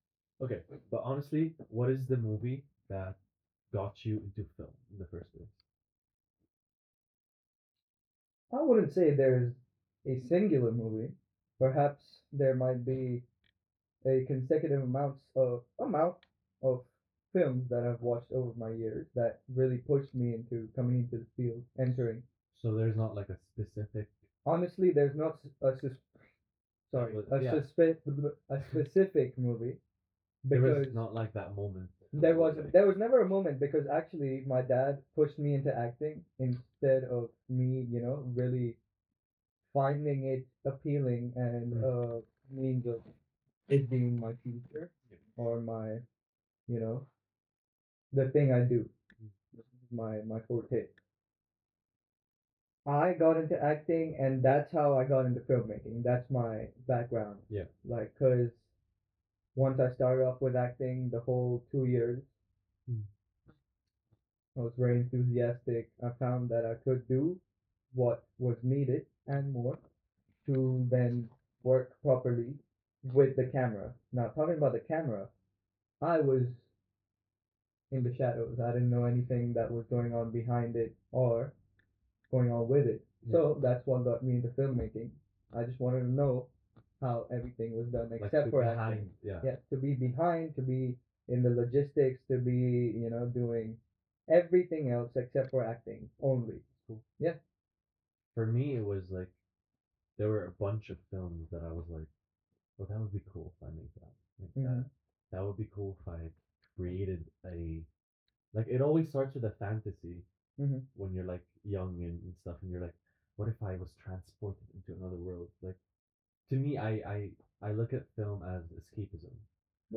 A Conversation of SAE Dubai Film Students